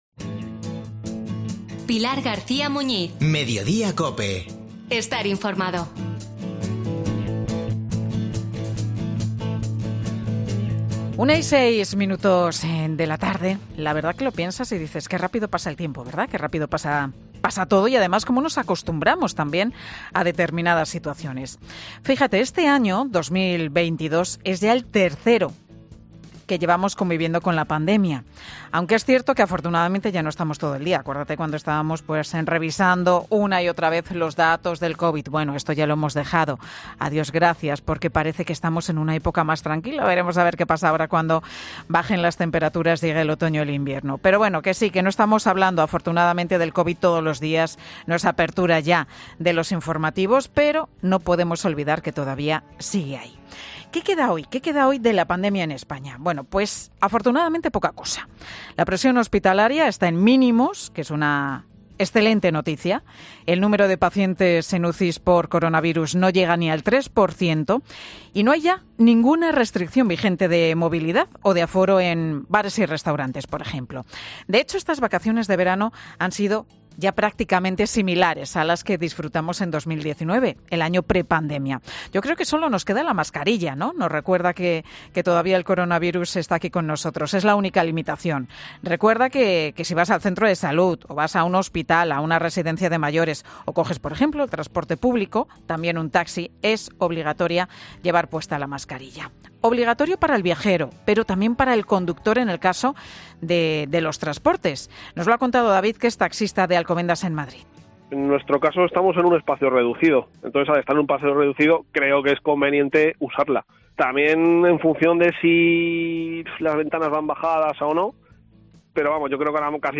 En 'Mediodía COPE' hablamos con un taxista que todavía sigue usando mascarilla en su trabajo y con un epidemiólogo que nos habla de la situación actual